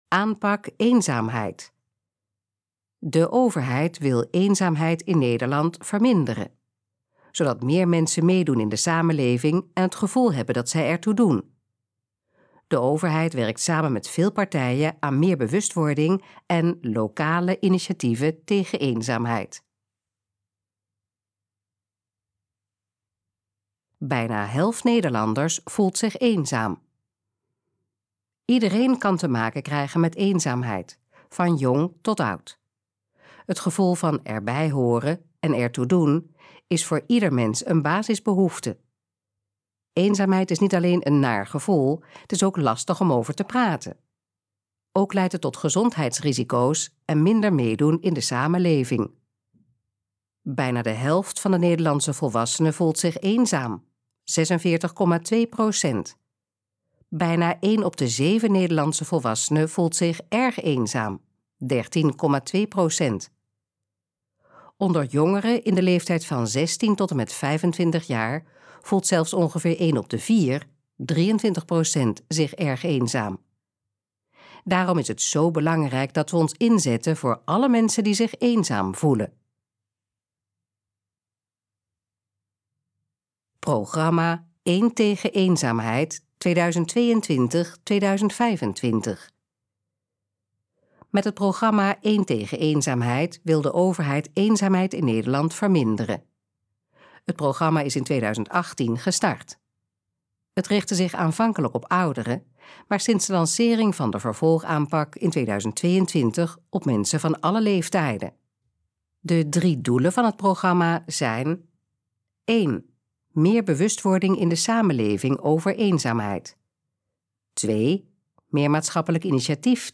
Dit geluidsfragment is de gesproken versie van de pagina: Aanpak eenzaamheid